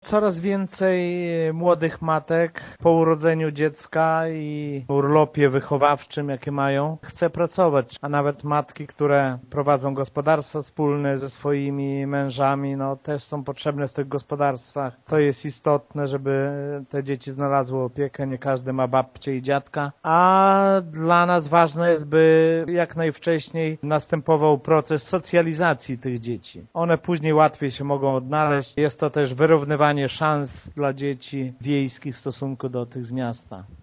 „Po rozmowach z rodzicami przedszkolaków ustalono, że część kosztów pokryją sami rodzice, którym bardzo zależy, aby miejsc w przedszkolach było jak najwięcej” – mówi wójt Lech Szopiński.